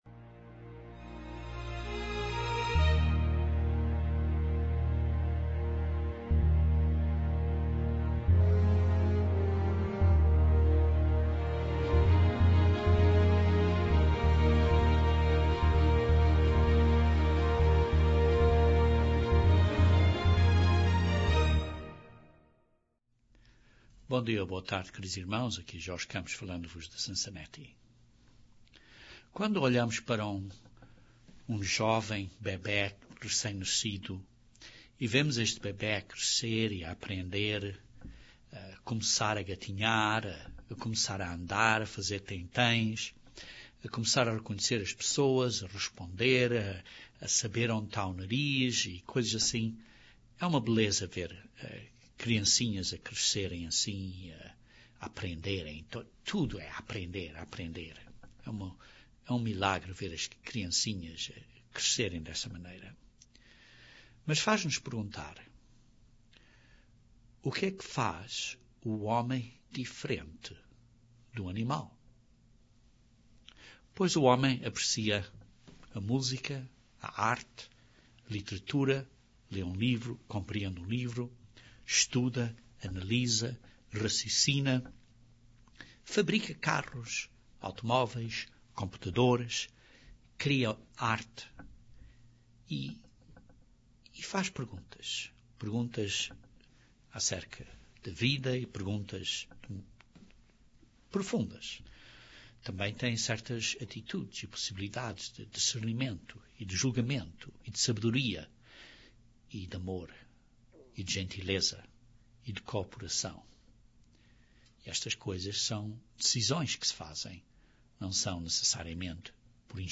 Este sermão demonstra a importância do espírito do homem no homem para a realização do plano de Deus para a humanidade.